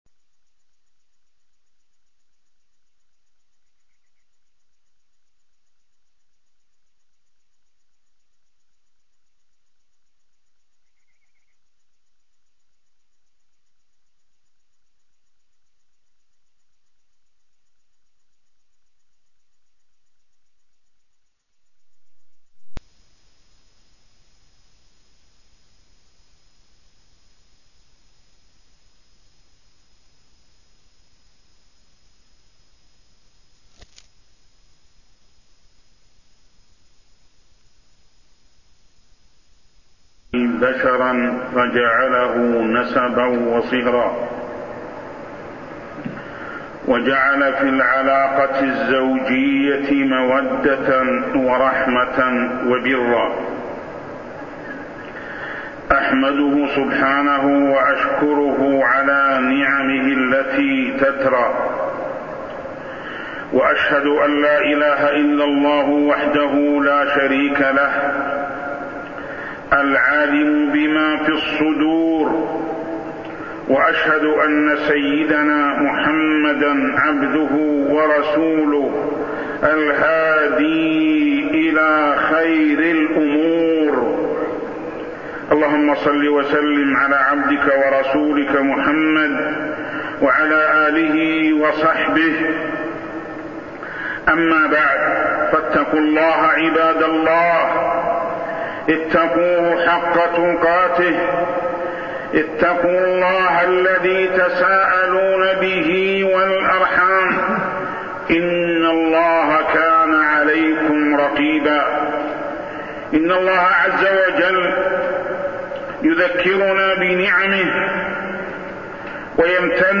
تاريخ النشر ٢٧ صفر ١٤١٢ هـ المكان: المسجد الحرام الشيخ: محمد بن عبد الله السبيل محمد بن عبد الله السبيل حسن الخلق بين الزوجين